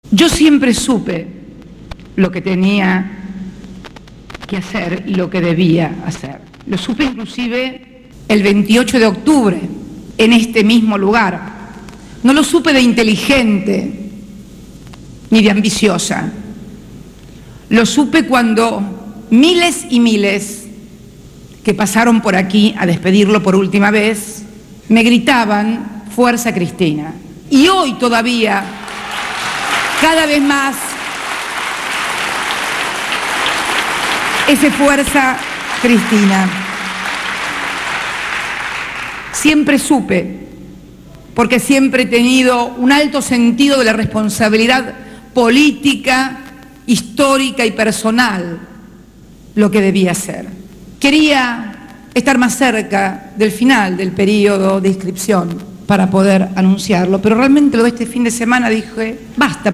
En la Casa de Gobierno, en el marco de la inauguración de la TV Digital para Jujuy y Entre Ríos, la Presidenta anunció su candidatura presidencial.